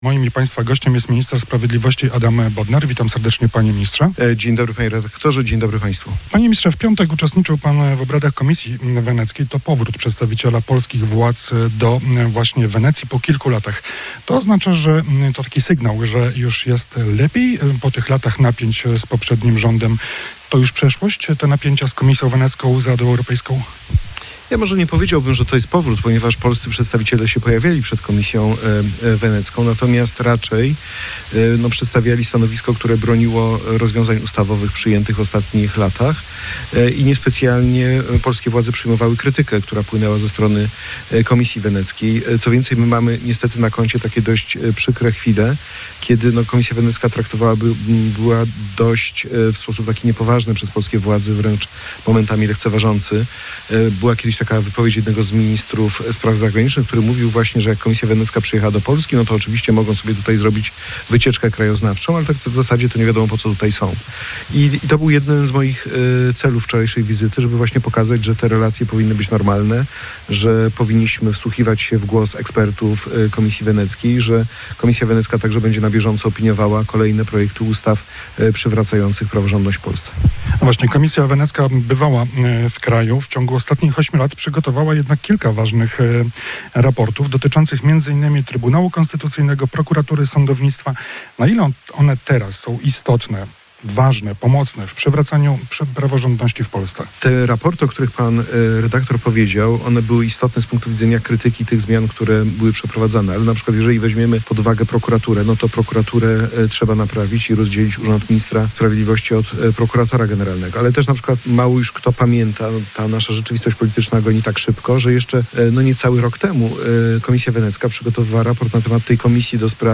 - Przed wakacjami musimy doprowadzić do uchwalenia kilku podstawowych ustaw. Przede wszystkim dotyczy to Krajowej Rady Sądownictwa – podkreślił w Kielcach minister sprawiedliwości Adam Bodnar.